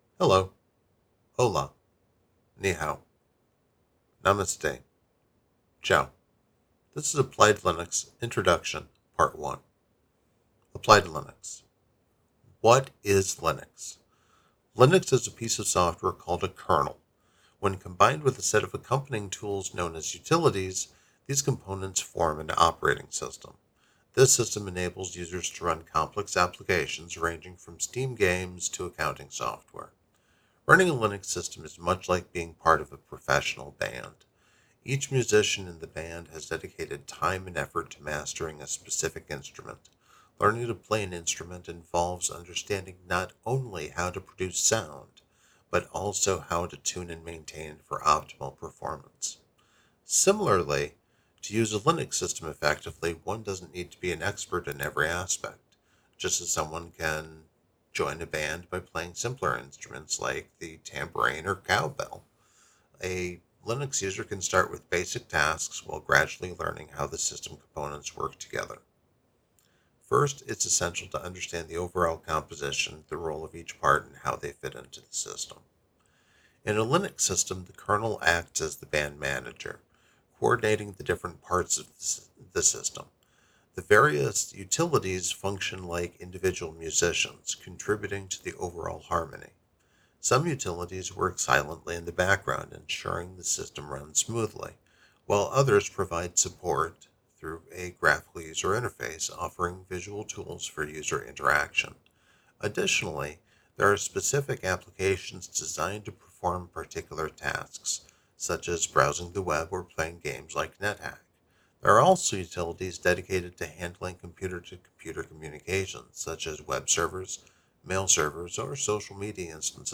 'Audiobook